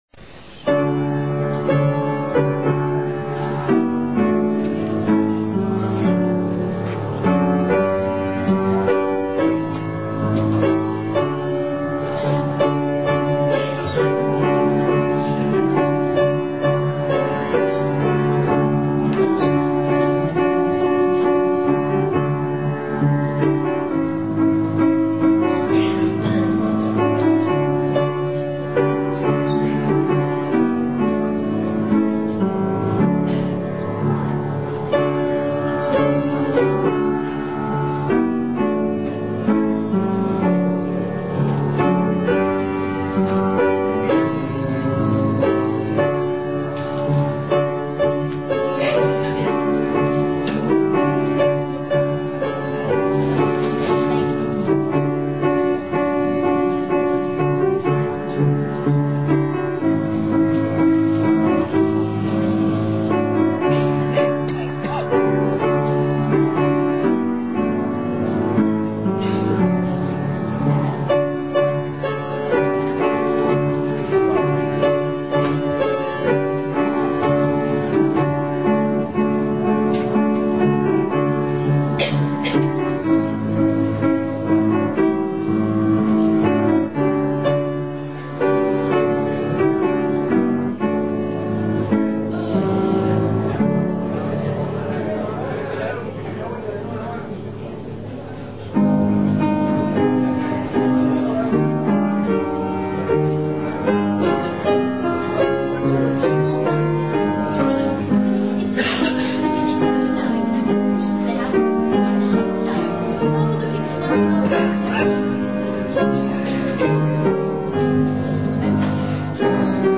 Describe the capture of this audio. Our Carol Service CarolService08.mp3